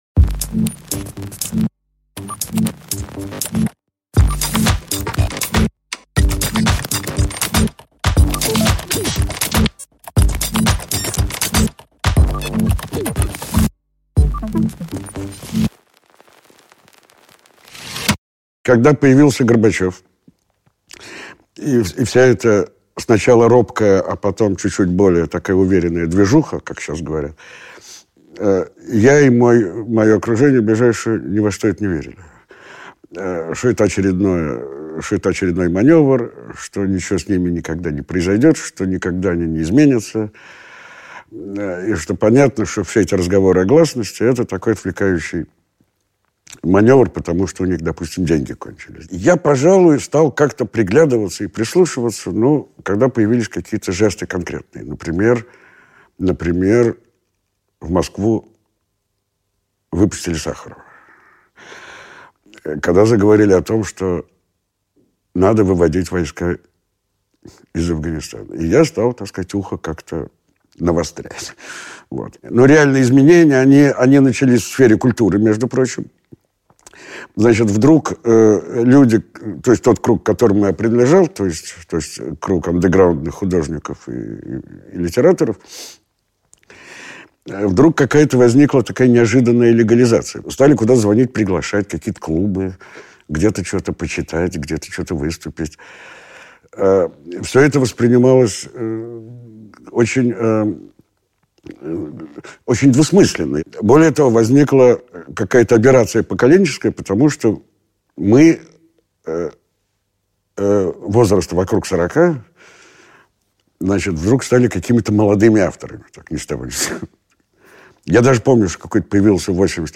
Аудиокнига Политика 90-х глазами интеллигенции | Библиотека аудиокниг